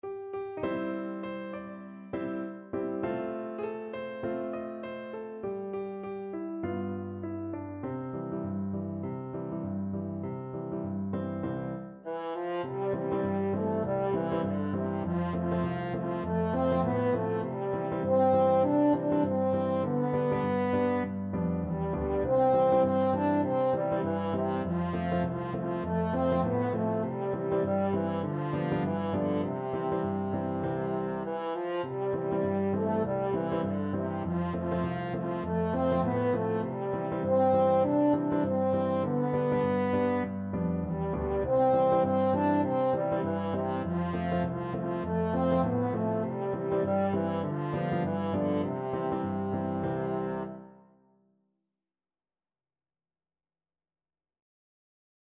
French Horn
C major (Sounding Pitch) G major (French Horn in F) (View more C major Music for French Horn )
4/4 (View more 4/4 Music)
Swing 16, =100
Traditional (View more Traditional French Horn Music)
wreck_97_HN.mp3